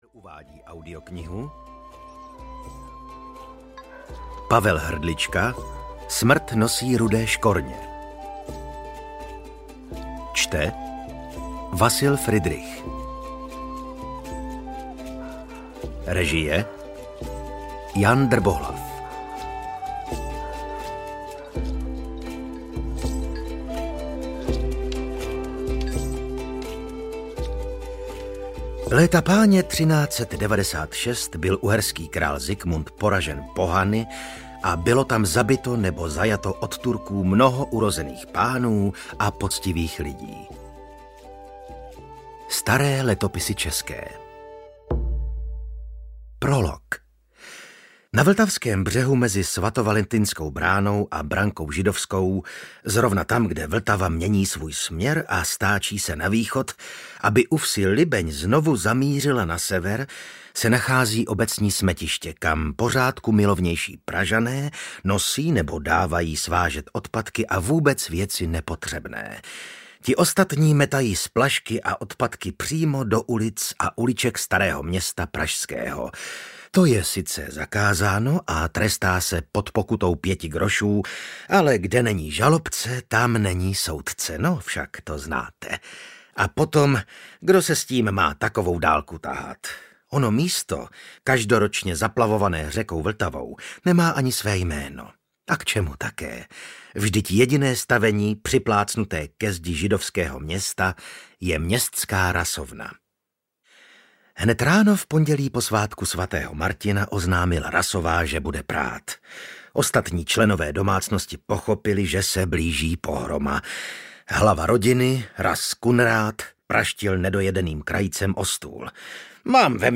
Smrt nosí rudé škorně audiokniha
Ukázka z knihy
• InterpretVasil Fridrich